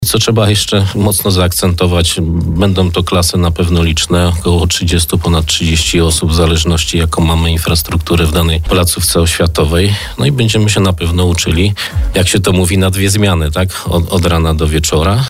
Zamknięto pierwszy etap rekrutacji do szkół ponadpodstawowych w Bielsku-Białej. Jak mówił na naszej antenie wiceprezydent miasta, w systemie zarejestrowało się ponad 5 tys. osób, wcześniej było to 3,5 tys. uczniów.